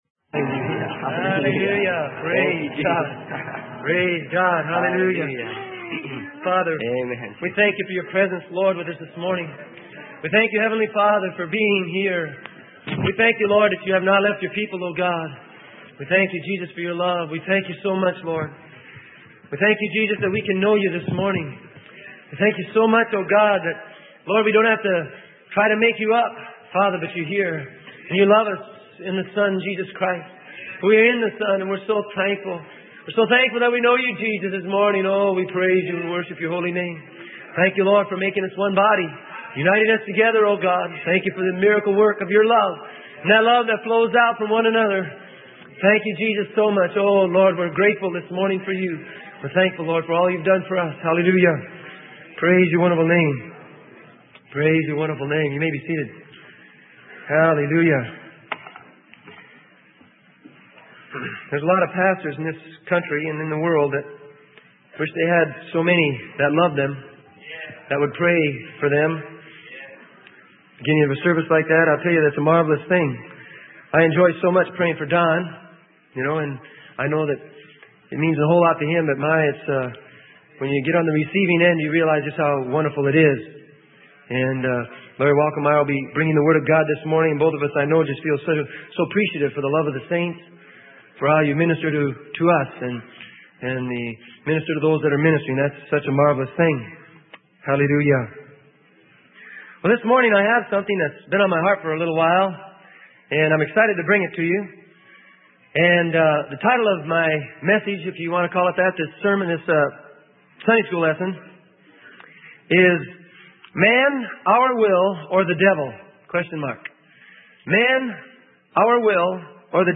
Sermon: Man - Our Will or the Devil?